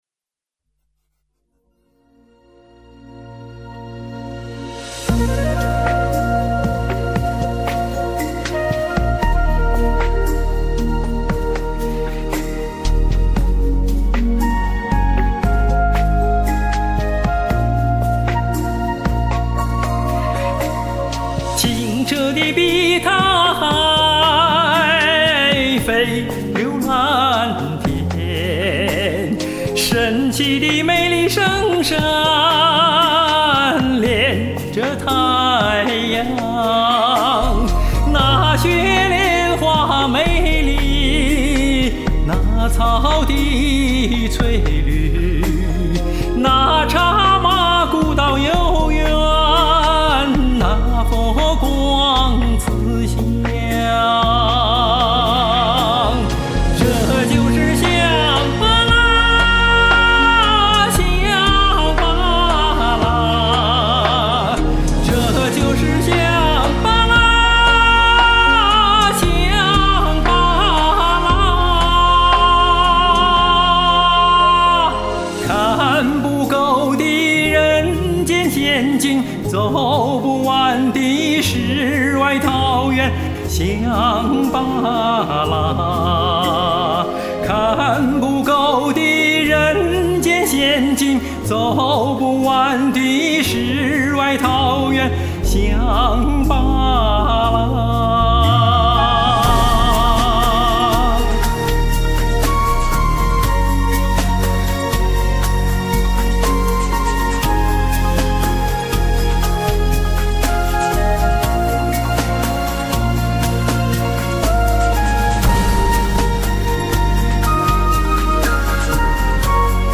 这首歌是我今年年初唱的，没有在高山贴过。